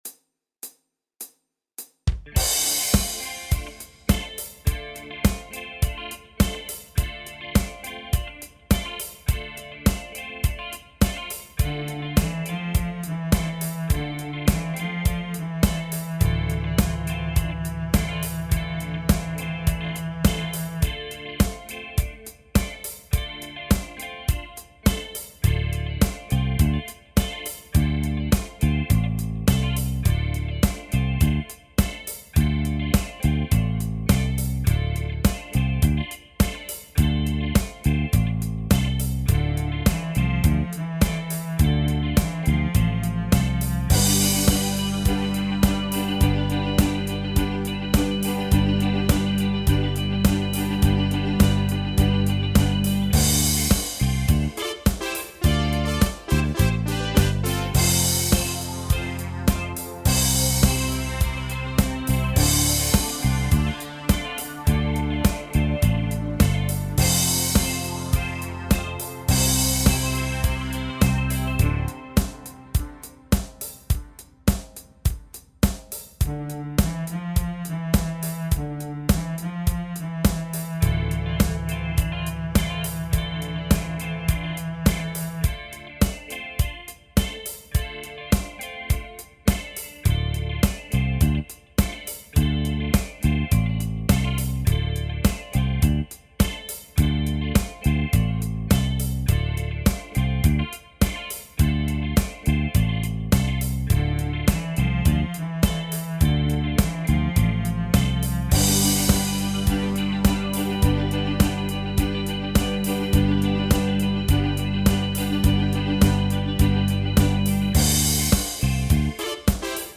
(Bass Guitar)